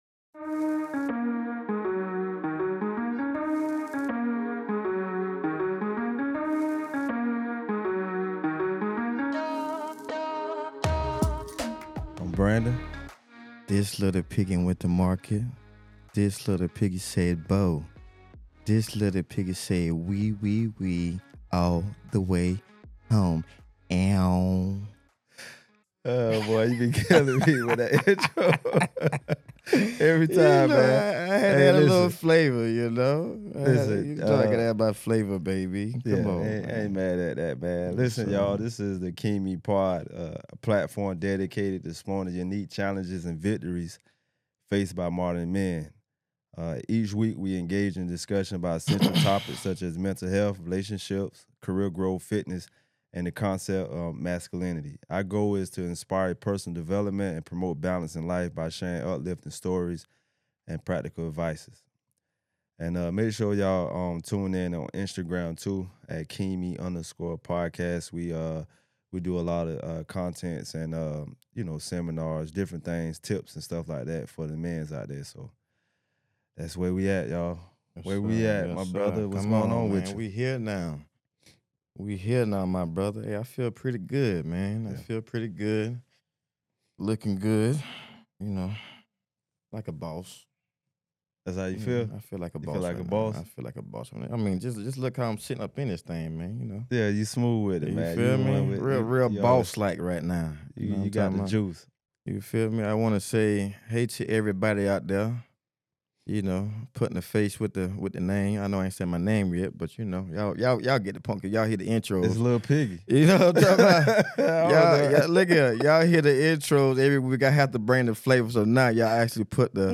This is a conversation about focus, discipline, and stepping into your full potential.